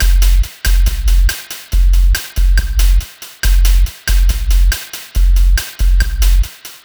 Cheese Lik Drumz 140.wav